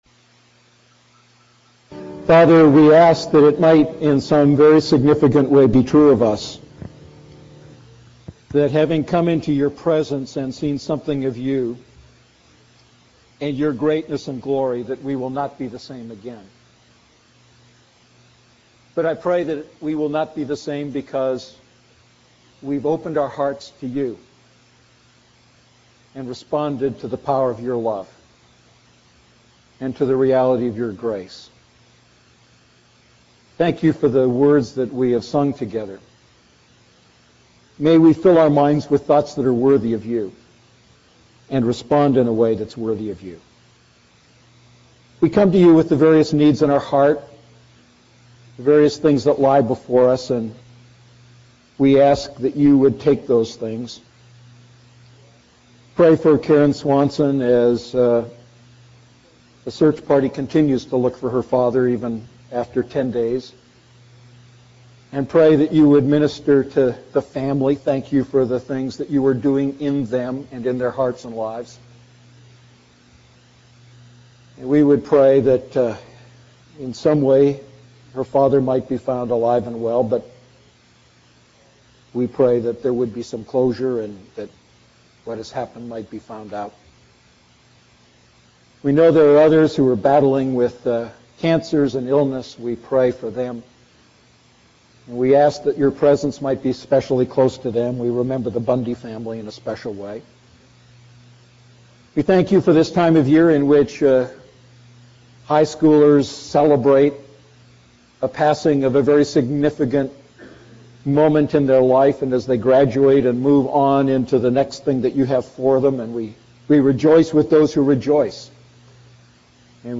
A message from the series "Defining Moments."